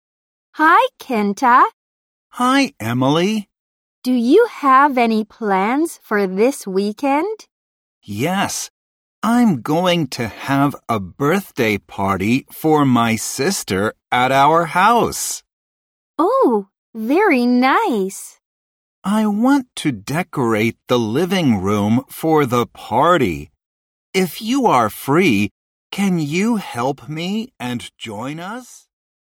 英語リスニング音源